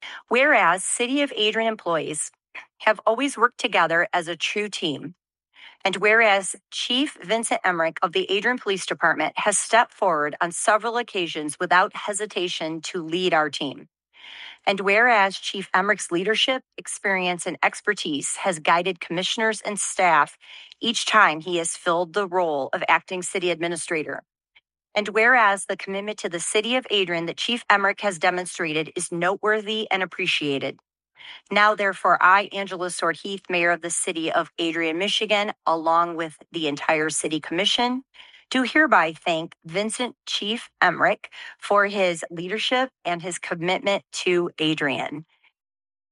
Mayor Angela Heath read a proclamation thanking Emrick for his leadership.
That was Mayor Heath.